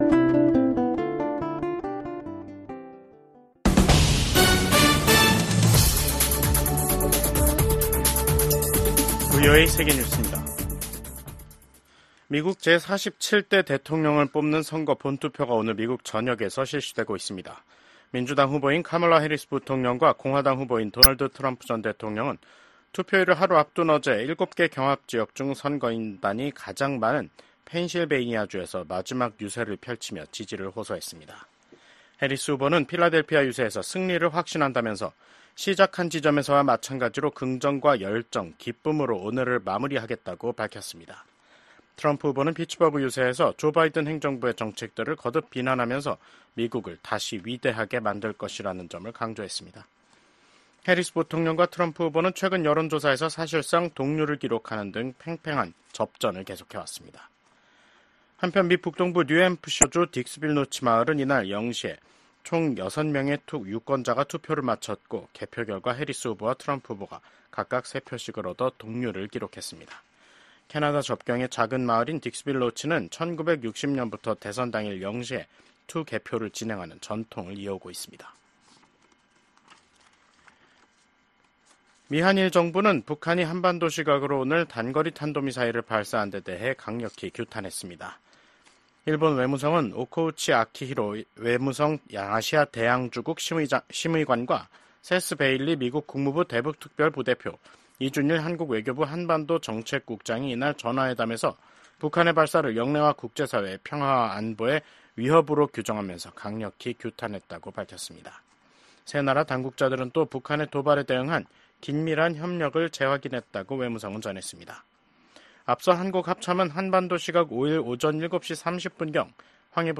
VOA 한국어 간판 뉴스 프로그램 '뉴스 투데이', 미국 대통령 선거일을 맞아 VOA가 보내드리는 뉴스 투데이 미국 대선 특집방송 3부 시작하겠습니다.